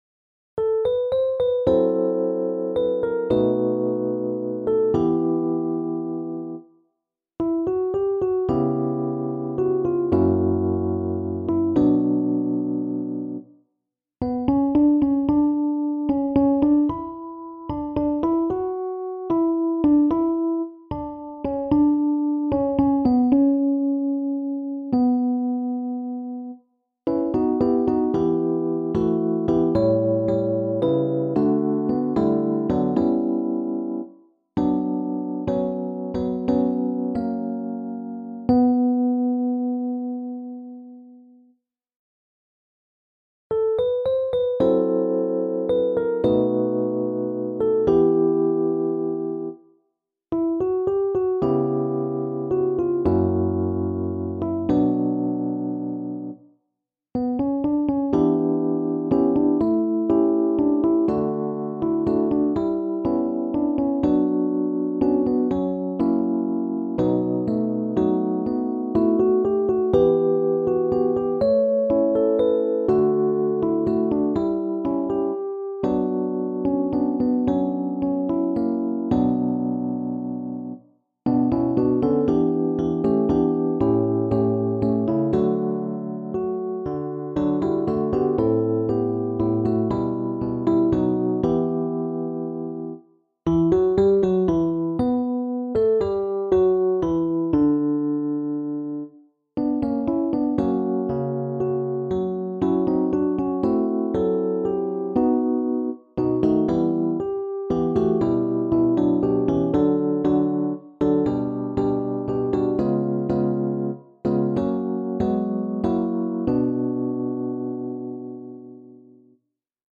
SSAA | SATB mit Mezzosopransolo | SSAB mit Mezzosopransolo
Ein zauberhaftes Lied